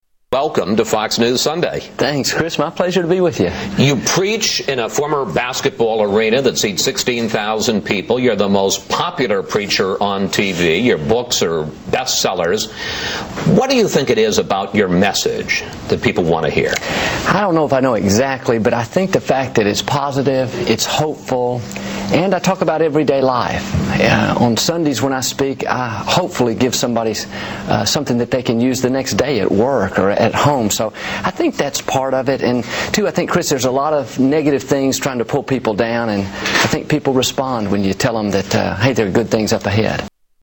Pastor Joel Osteen Interview